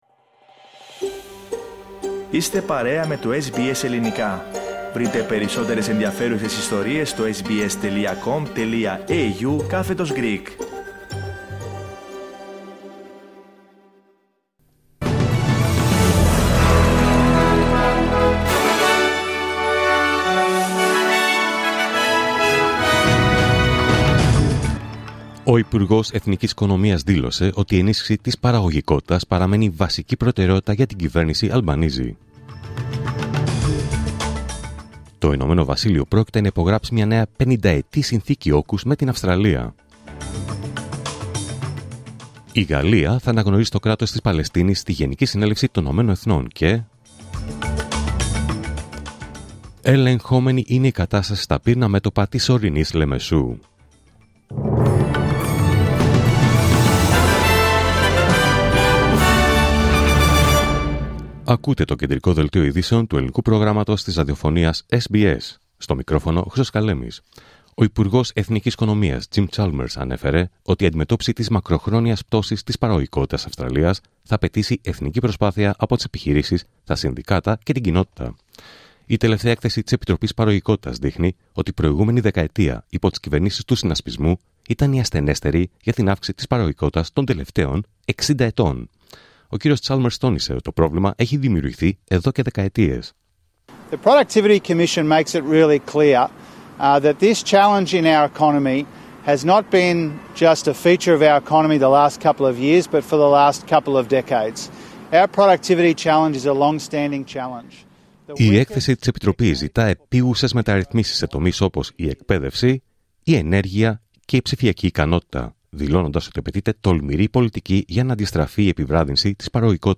Δελτίο Ειδήσεων Παρασκευή 25 Ιουλίου 2025